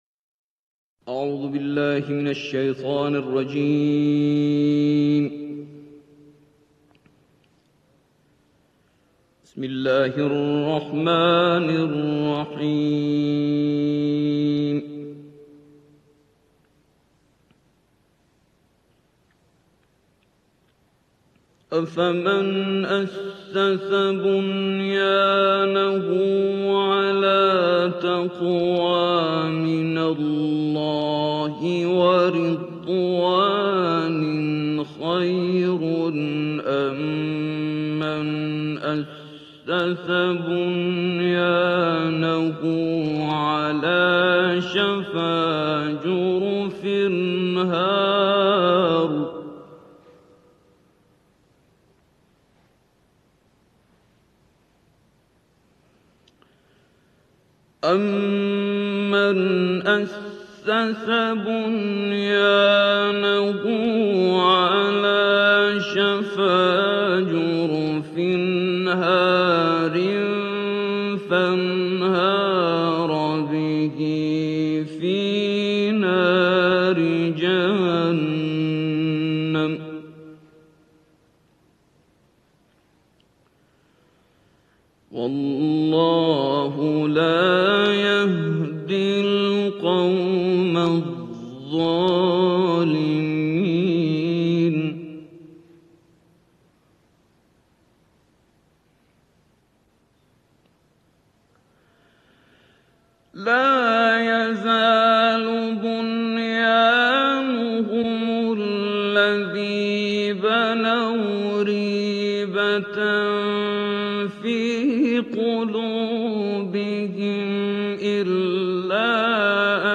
تلاوت قرآن